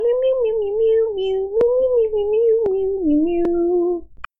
Meow2